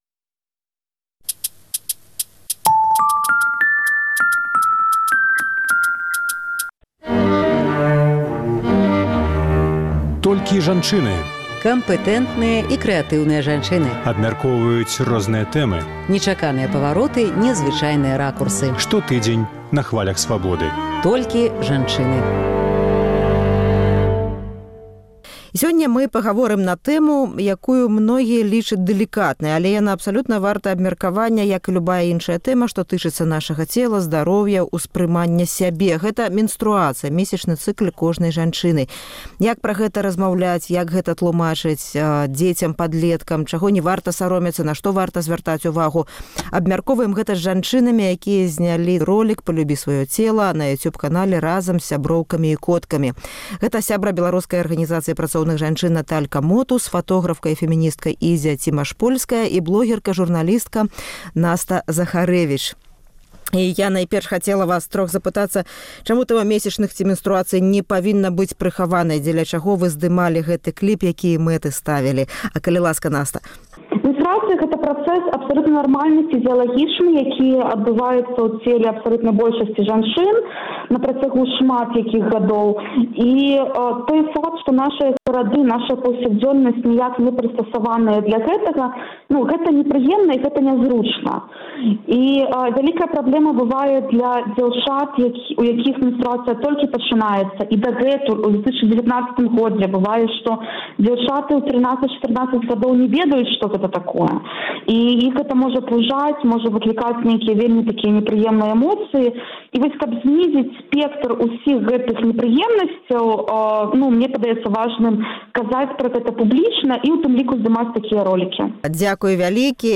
Kампэтэнтныя і крэатыўныя жанчыны абмяркоўваюць розныя тэмы, нечаканыя павароты, незвычайныя ракурсы.